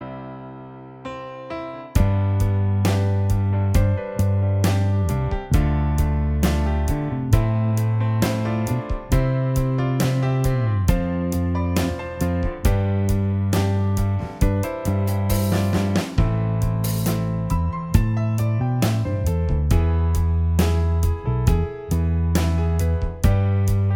Minus Guitars Rock 4:35 Buy £1.50